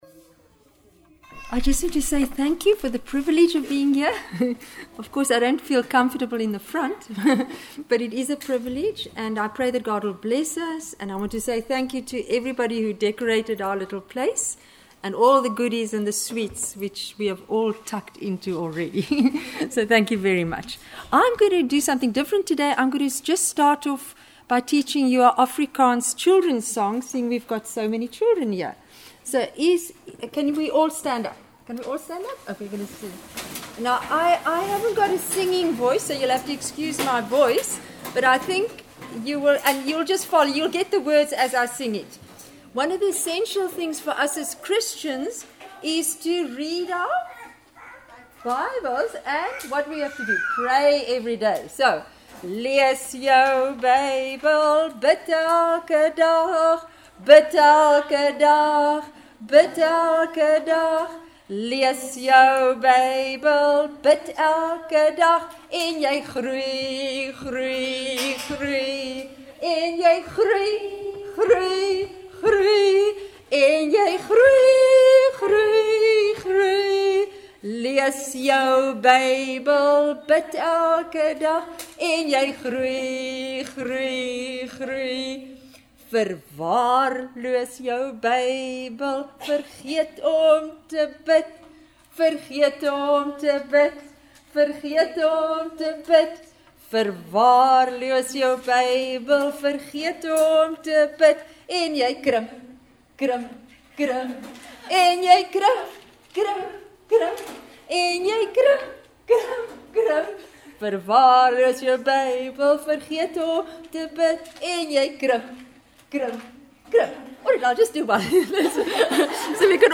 In this sermon, the speaker reflects on the state of a house that was once a shining example of God's creative power.